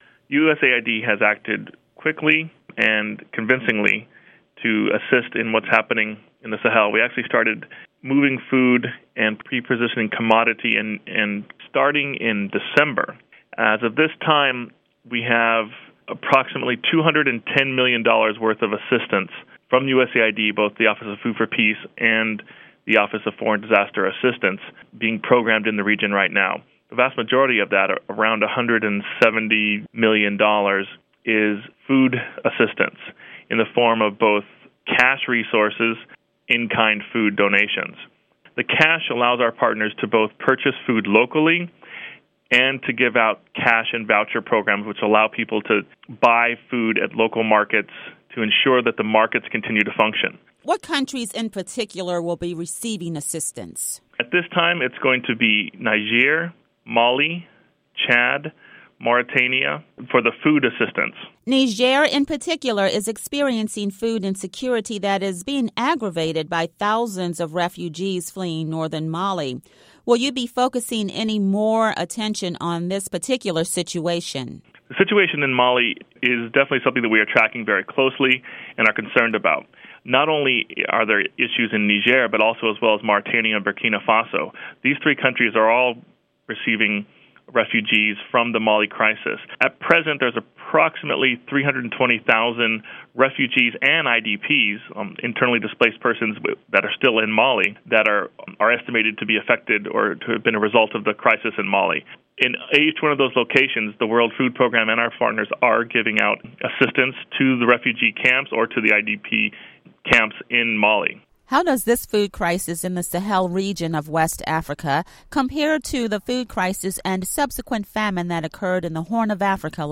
report on USAID and Sahel